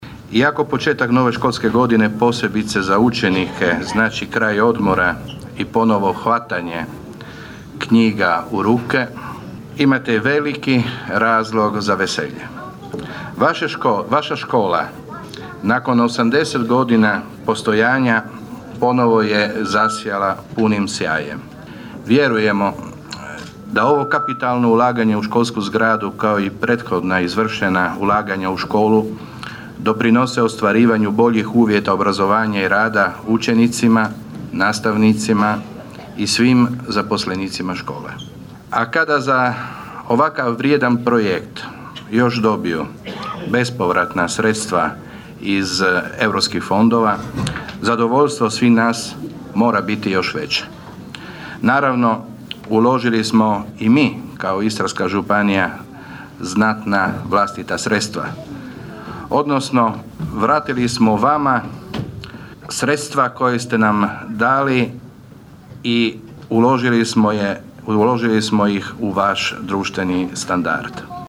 ton – Fabrizio Radin), kazao je na otvorenju zamjenik župana obnašatelj dužnosti župana Istarske županije Fabrizio Radin.